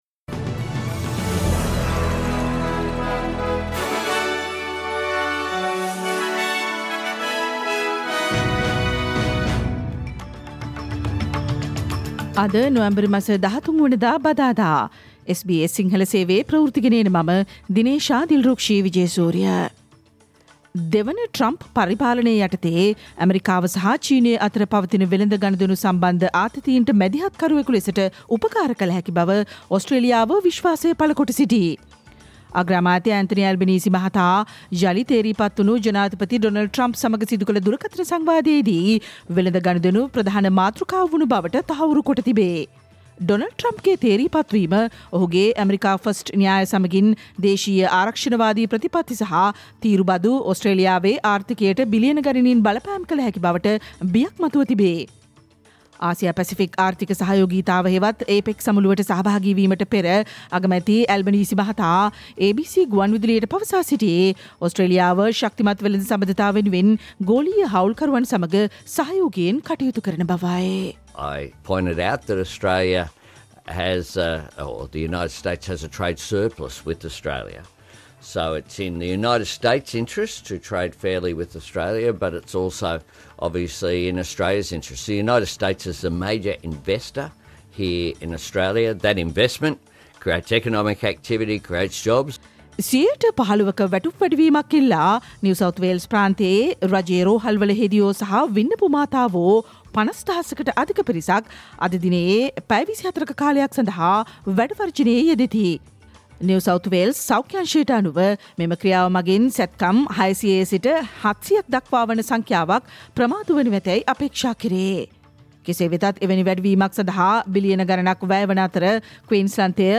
Australian news in Sinhala, foreign and sports news in brief - listen, SBS Sinhala radio news on Wednesday 13 November 2024